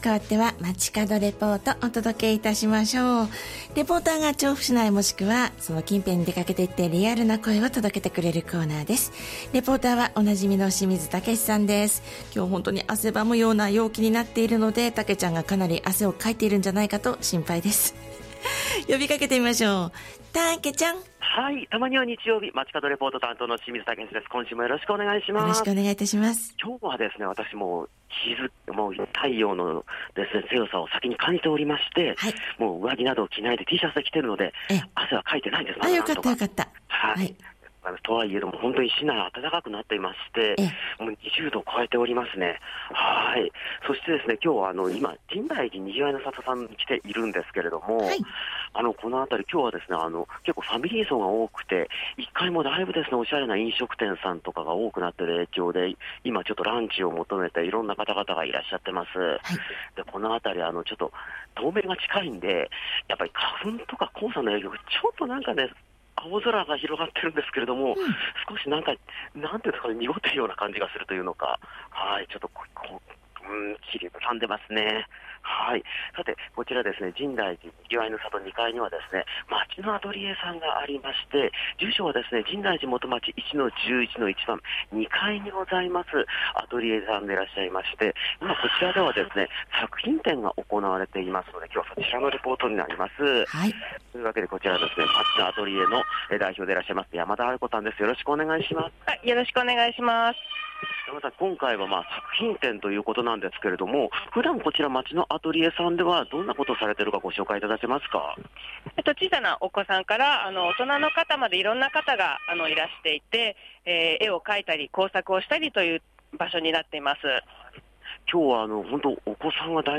先週の肌寒さから一転、夏日の様な気候の中からお届けした本日の街角レポートは、 「街のアトリエ １号室」さんから、開催中の『作品展』についてレポートです！